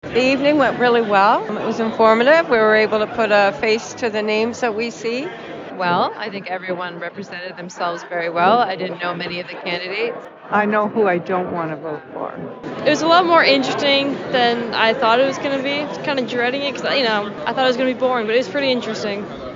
More than 100 people attended an all-candidates town hall presented by RogersTV London at the Strathroy Portuguese Canadian Club Tuesday evening for the upcoming Federal election, featuring all six candidates in the new Middlesex-London riding. myFM asked the attendees what they thought of the event.